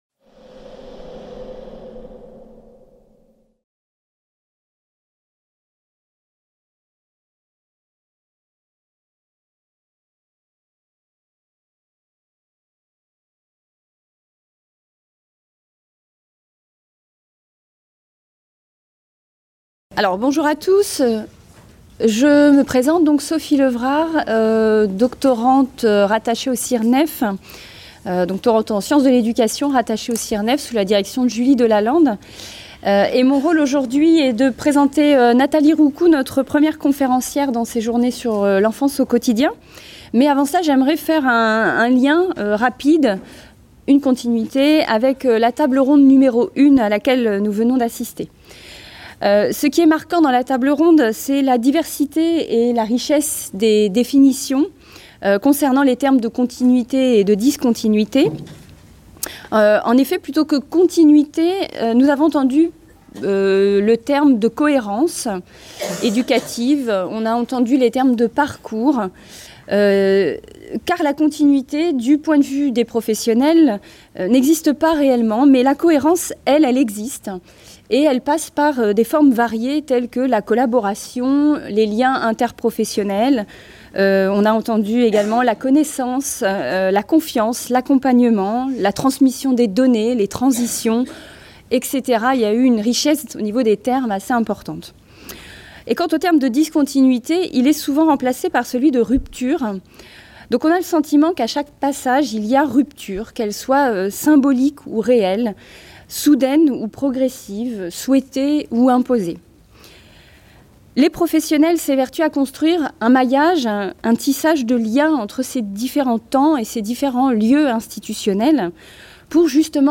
CIRNEF18 | 02 - Conférence 1 : Loisir et éducation informelle. Pour une discontinuité éducative | Canal U